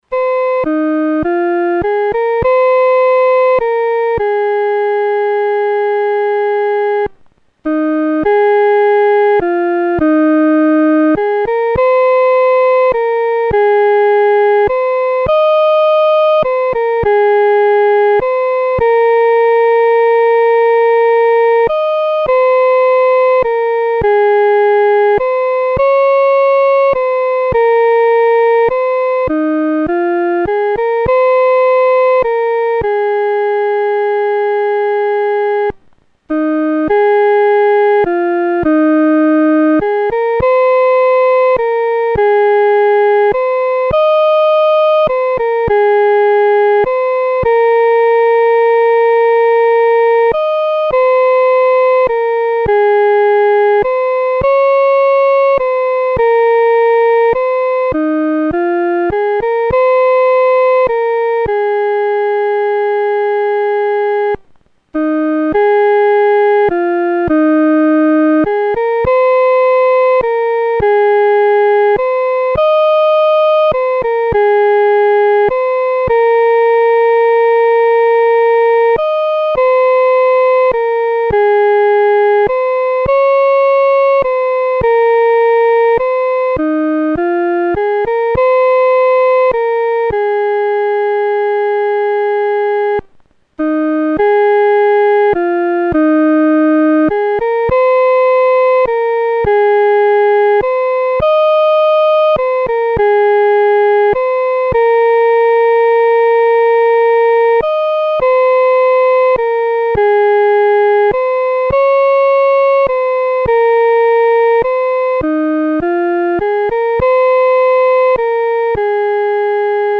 女高伴奏